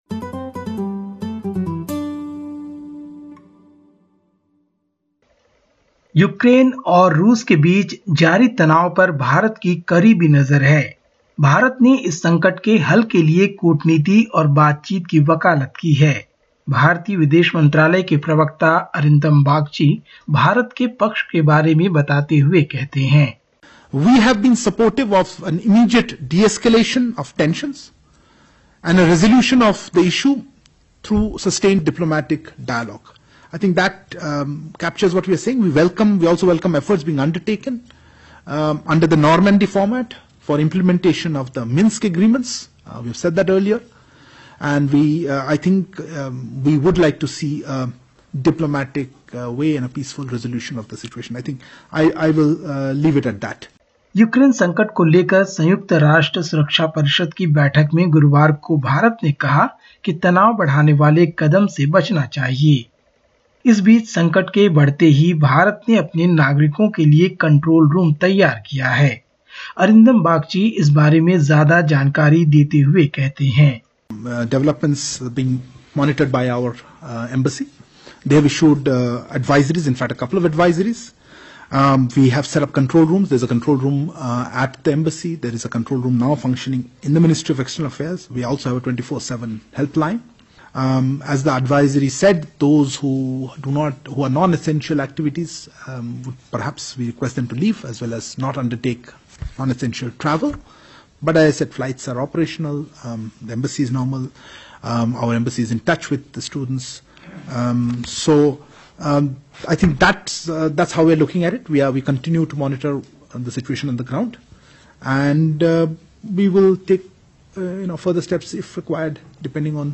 Listen to the latest SBS Hindi report from India. 18/02/2022